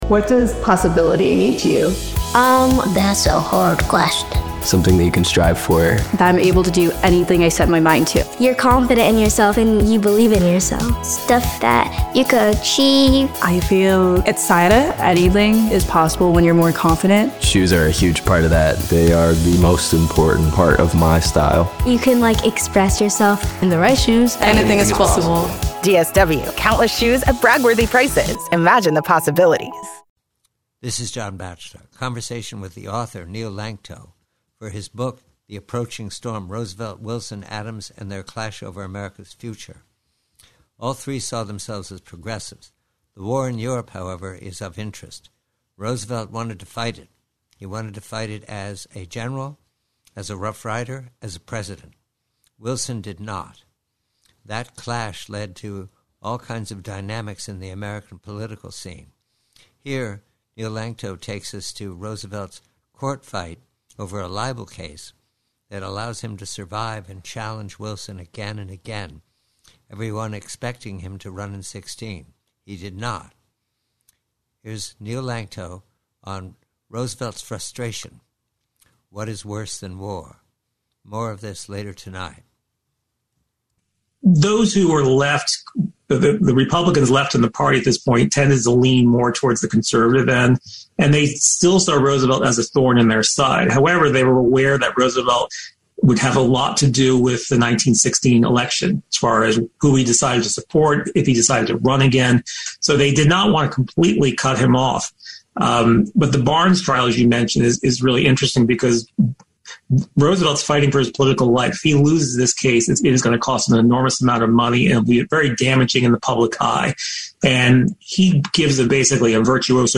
PREVIEW: Conversation with historian